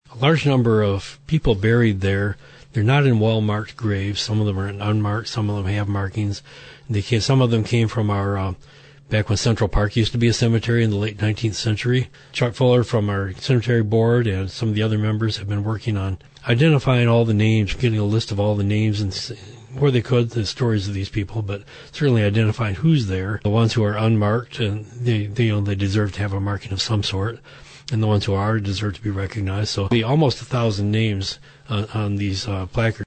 Mayor Bob Monetza: